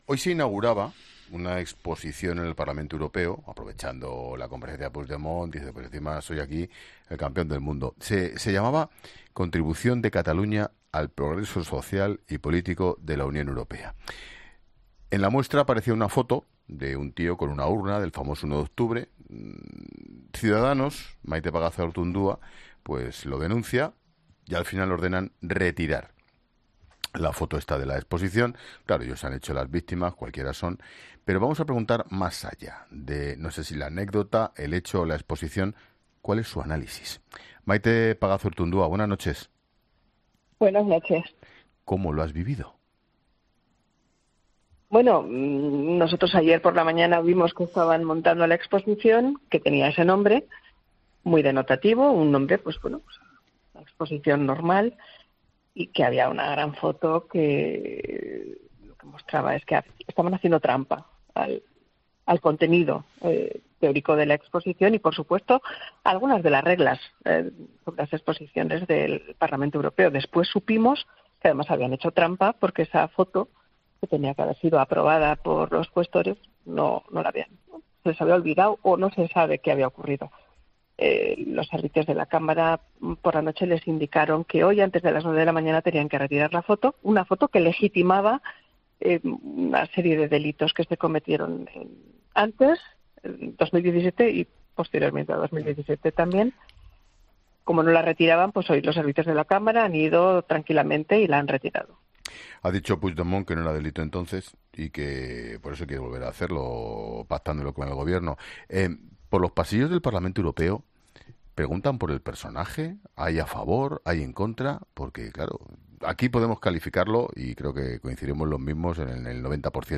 Maite Pagazaurtundúa ha pasado por los micrófonos de La Linterna , donde ha explicado que la imagen “no había sido aprobada”.